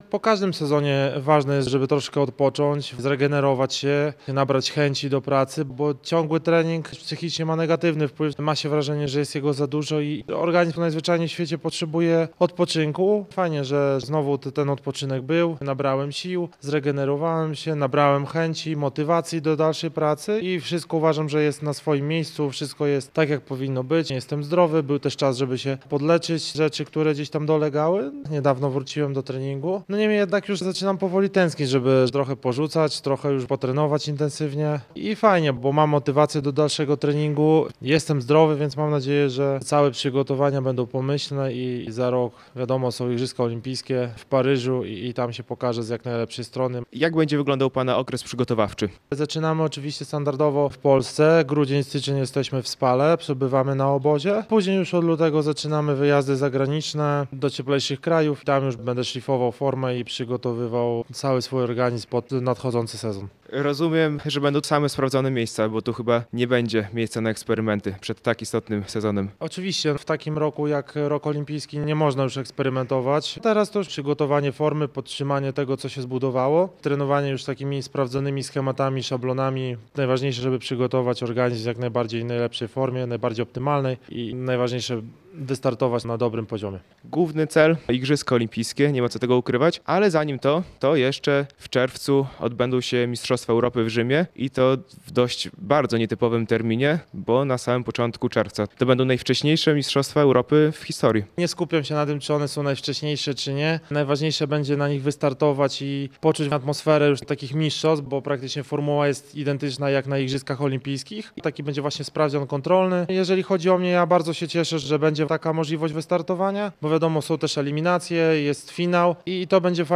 Radio Białystok | Gość | Wojciech Nowicki - młociarz Podlasia Białystok, mistrz olimpijski
młociarz Podlasia Białystok, mistrz olimpijski